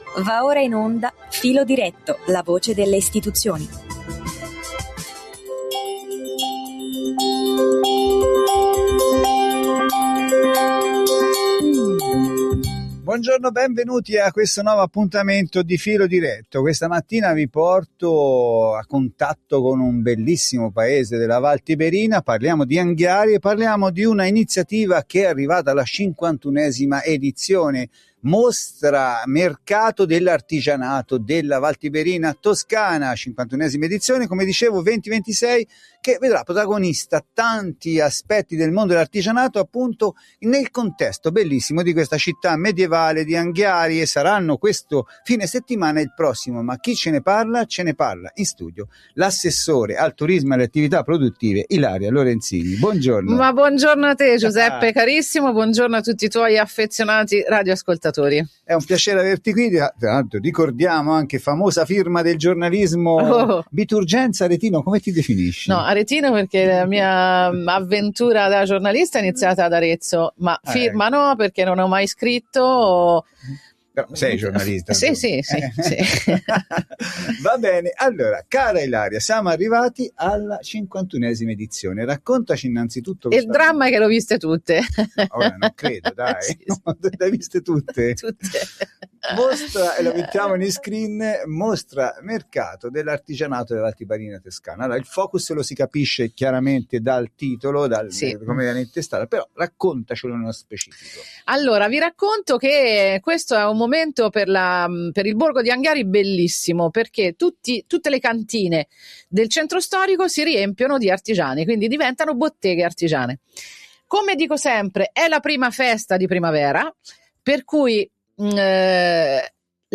Evento che si terrà il 24-25-26 Aprile e 1-2-3 Maggio ad Anghiari. In studio l’assessore al turismo e alle attività produttive Ilaria Lorenzini.